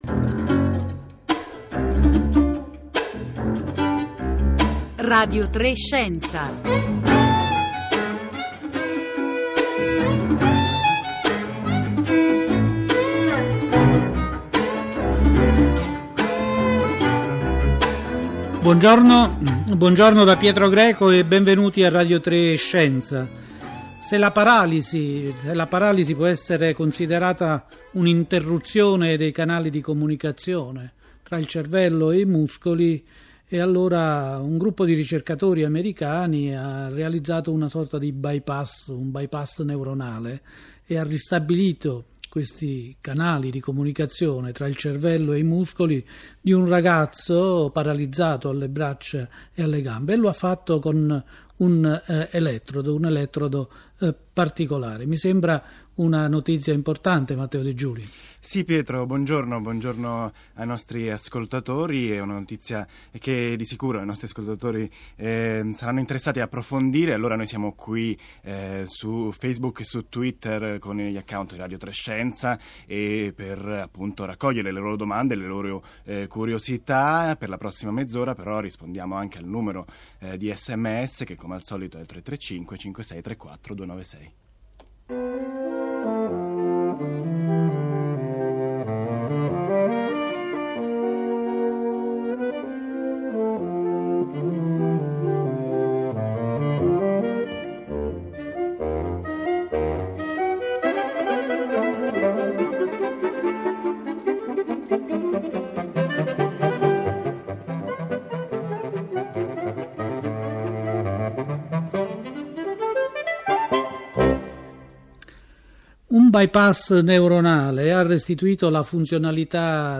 Al telefono, s'intende.